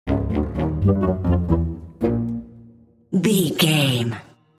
Funny musical phrase in orchestra style.
Atonal
DAW, orchestral samples
bright
joyful
chaotic
frantic
bouncy
percussion
flutes
oboe
strings
trumpet
brass